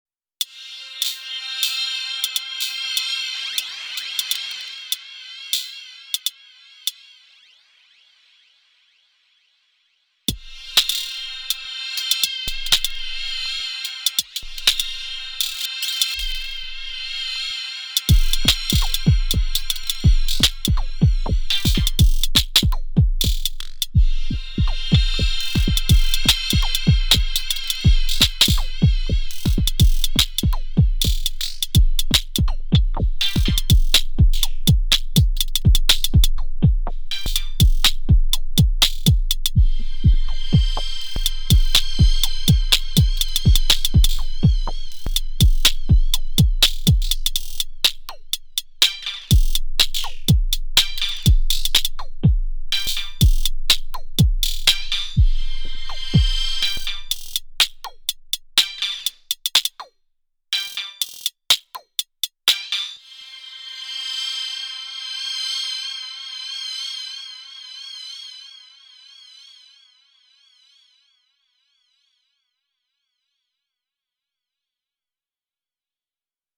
This is 8 tracks of cy alloy with a lot of fx track automation.
Oh I really like this.
I really like the non percussive sounds in particular.